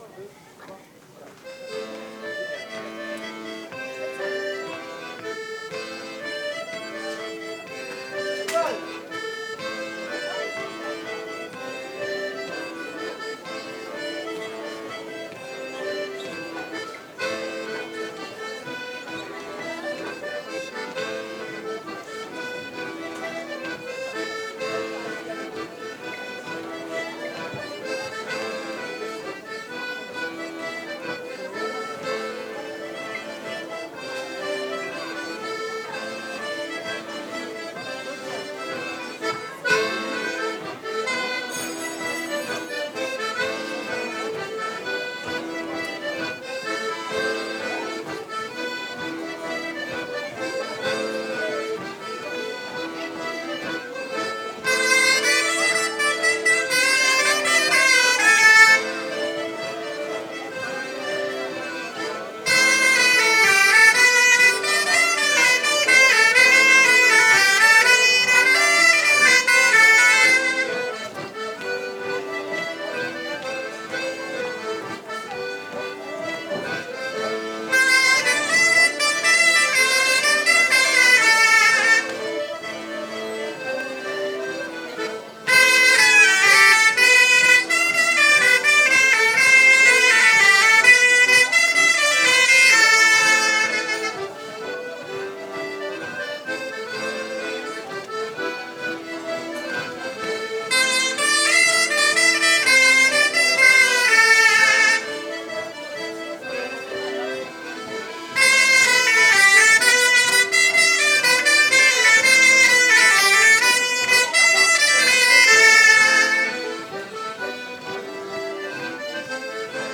14_loudia_baleu_ronds2-divers_instruments.mp3